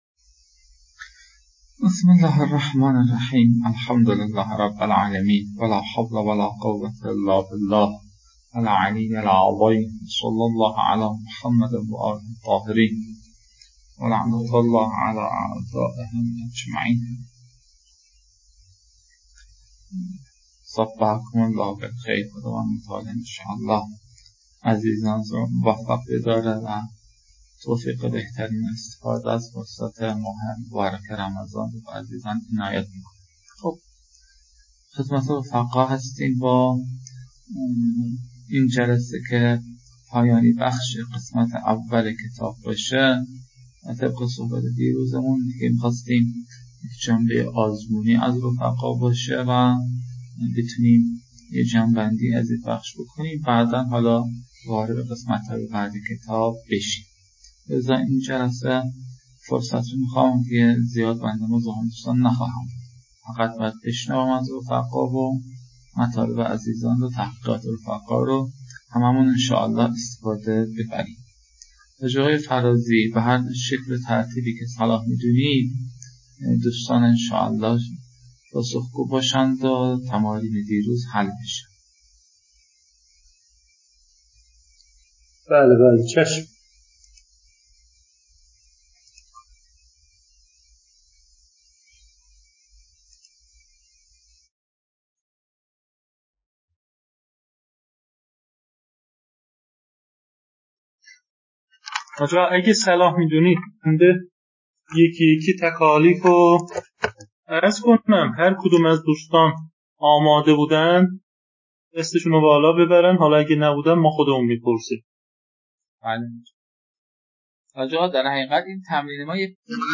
🔸 لازم به‌ذکر است که نتیجه این رویکرد، صرف پاره‌ای از بازه کلاس به رفت‌وبرگشت مبحث بین استاد و مخاطبان است که در کنار مجازی برگزارشدن کلاس، حوصله خاصی را در گوش دادن می‌طلبد. (البته فایل‌های صوتی بارها ویرایش شده‌اند تا کیفیت بهتر و مفیدتری داشته باشند.)